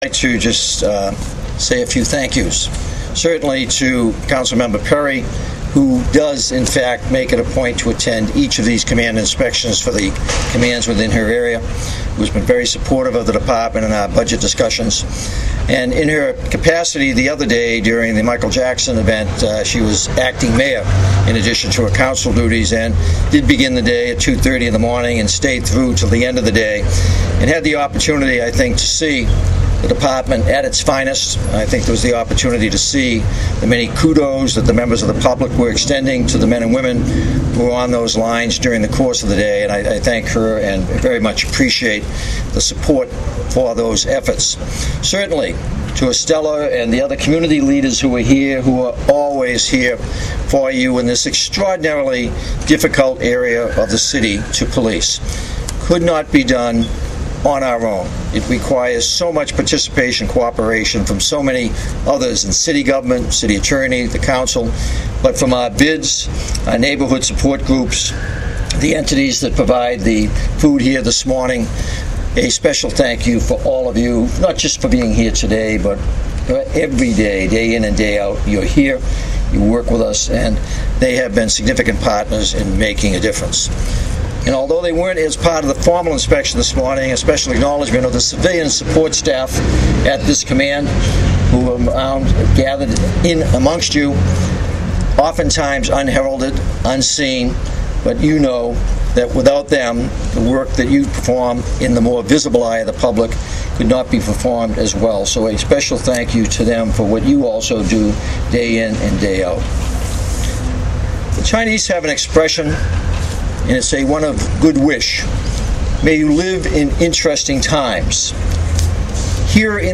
This morning, Central Area's finest were proudly on display as they held its annual Formal Command Inspection.